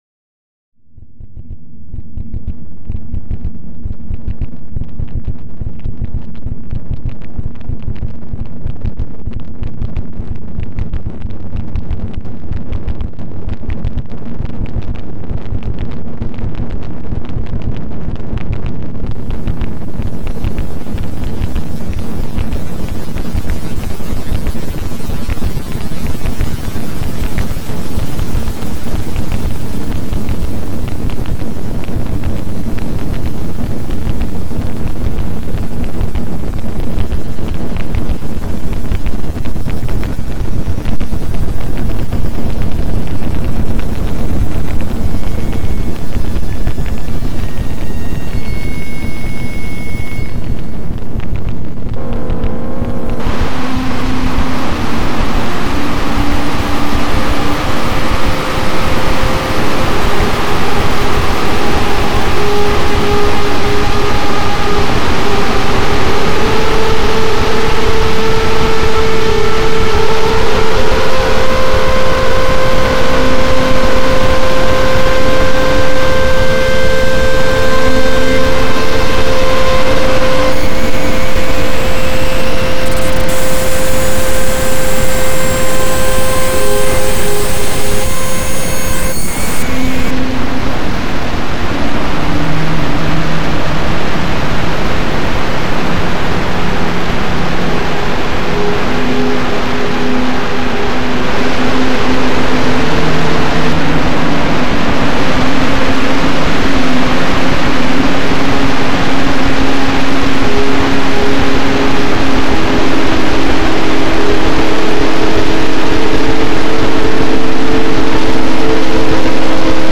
Made in France and Brazil, September 2008.